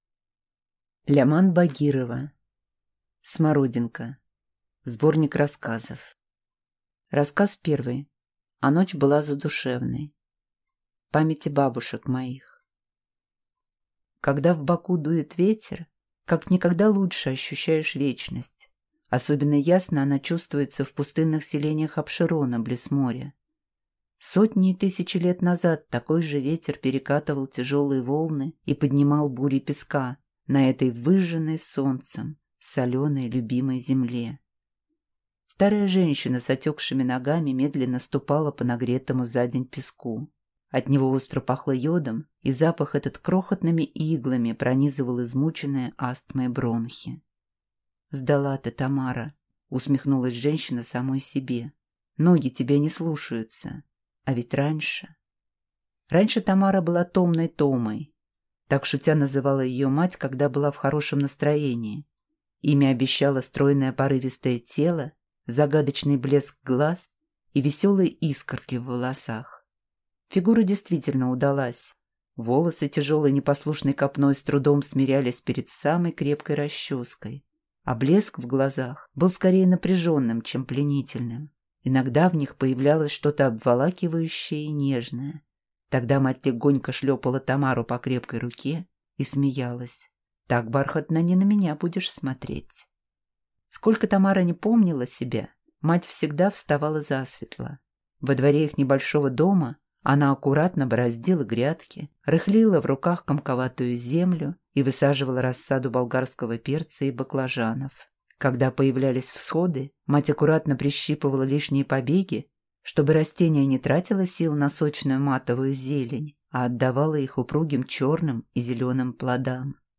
Аудиокнига Смородинка (сборник) | Библиотека аудиокниг